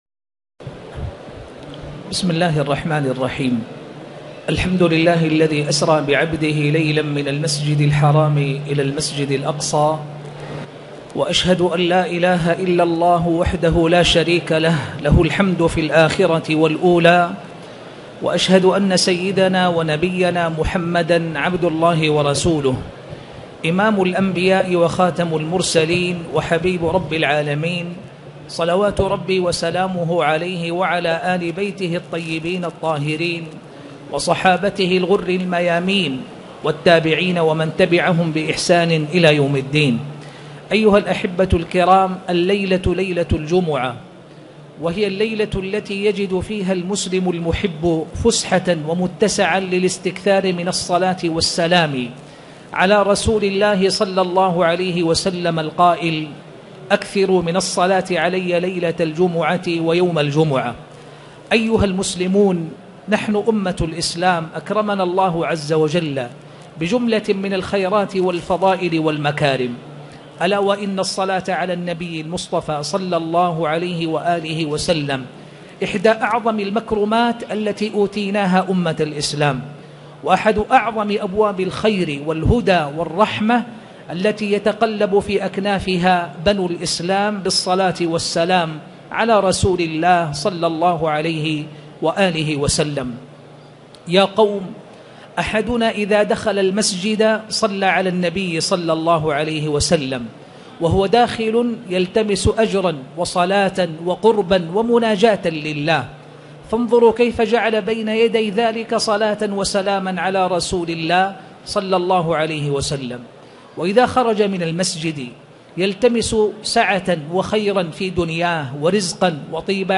تاريخ النشر ١٢ جمادى الأولى ١٤٣٨ هـ المكان: المسجد الحرام الشيخ